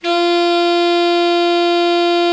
Index of /90_sSampleCDs/Giga Samples Collection/Sax/ALTO SAX